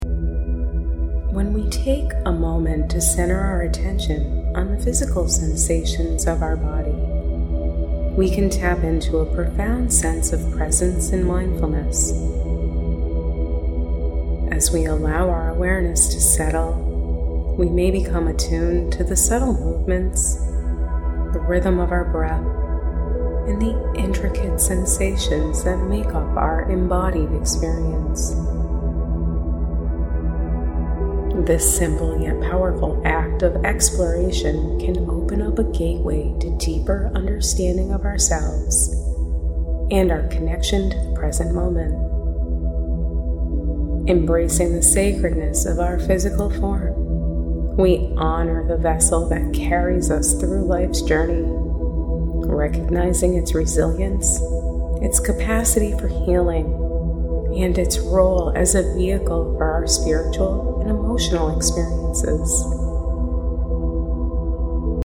Music: Earth by Maura ten Hoopen features healing frequencies to enhance your meditation experience.